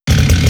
sfx_chainsaw_idle_1.wav